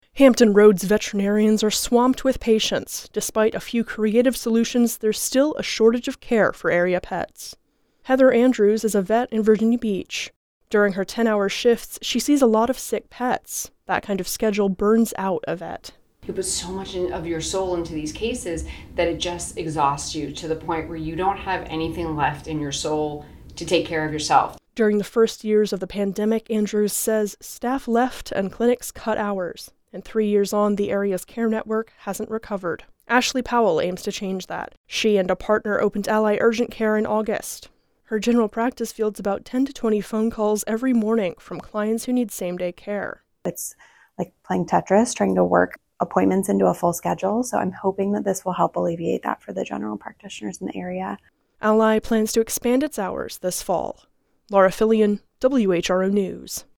WHRO Local News Report: As veterinarians in Hampton Roads try to manage burnout, number of pets still outpaces care